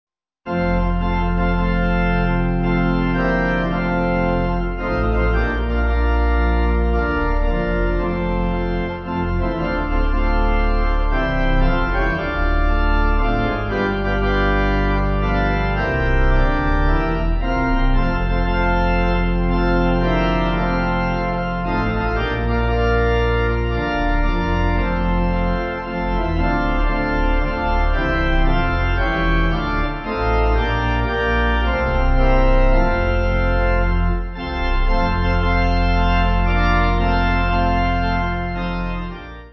Organ
Key change on last chorus